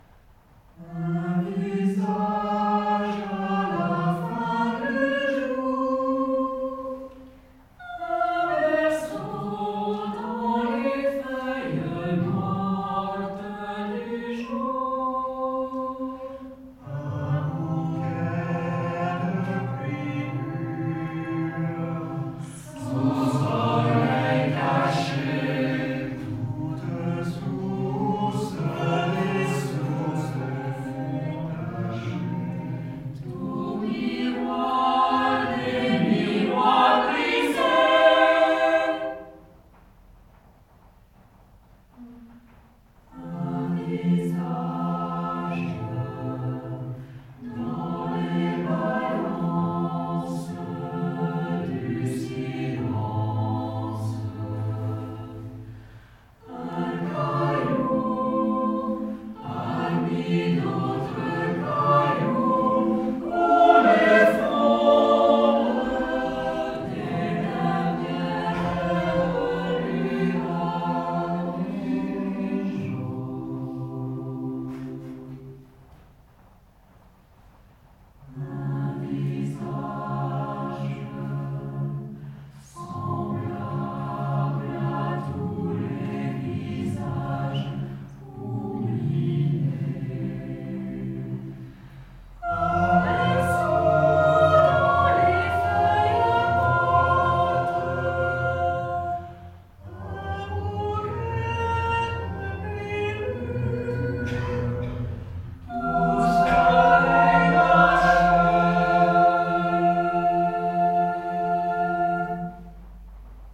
Belle et ressemblante - Francis Poulenc - Ensemble Vocal Totus